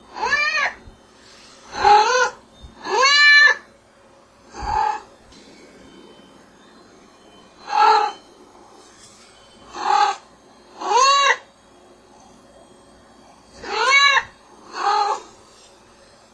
动物、野兽和怪兽 " 喵星人
描述：中级喵喵。 （没有动物受到伤害 这个声音由我自己进行并记录）。
标签： 喵喵叫 动物 小猫
声道立体声